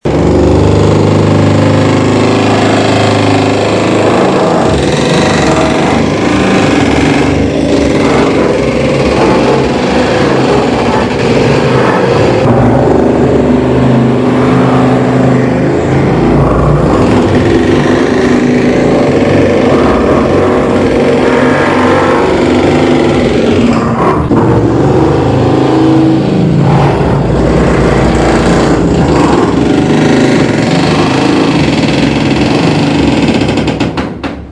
In order to make it livable, it must be “decorated” – requiring several months of construction activity to install flooring, wiring, plumbing, a kitchen, etc.  Our upstairs neighbor recently hired “decorators,” and the hammer-drilling was often unbearable: click here to listen:
Hammerdrilling
The deafening sound and vibrations make you wonder if the ceiling will collapse.
Hammerdrilling.mp3